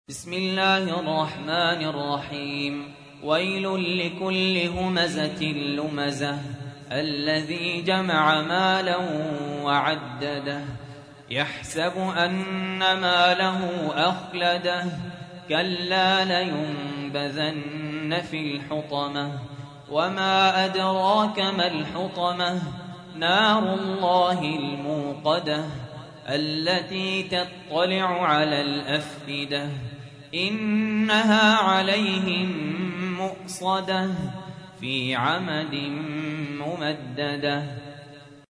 تحميل : 104. سورة الهمزة / القارئ سهل ياسين / القرآن الكريم / موقع يا حسين